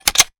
weapon_foley_pickup_10.wav